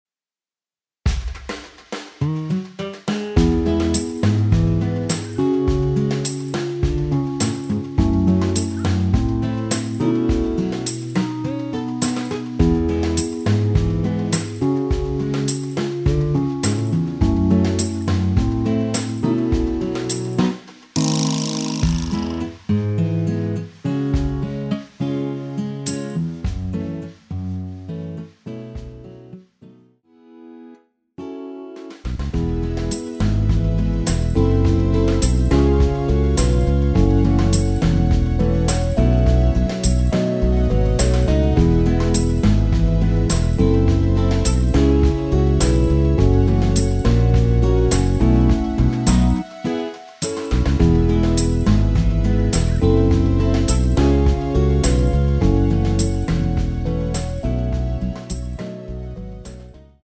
MR입니다
앞부분30초, 뒷부분30초씩 편집해서 올려 드리고 있습니다.
중간에 음이 끈어지고 다시 나오는 이유는